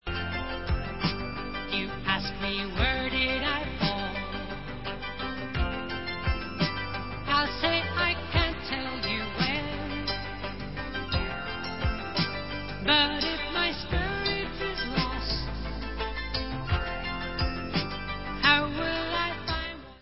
Pop/Symphonic